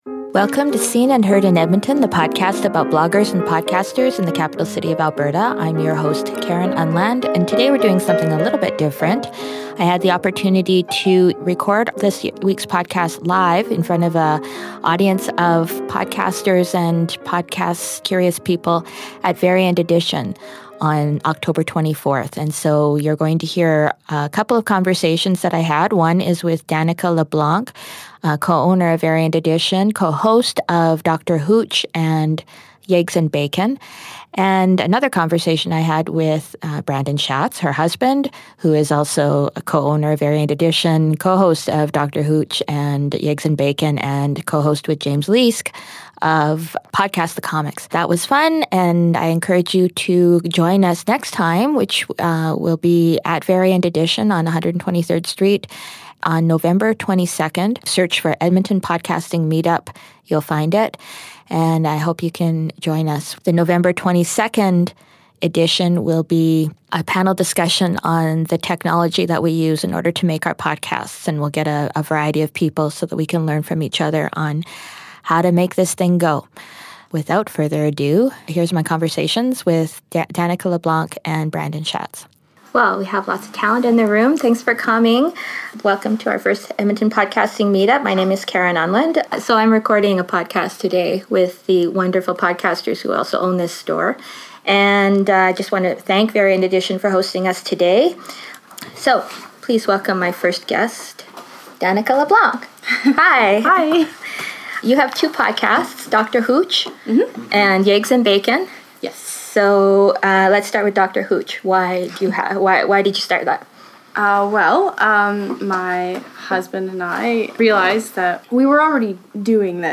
Since they are podcasters extraordinaire, I interviewed them in front of a live audience at the meetup, and that’s what you’ll hear on this week’s episode.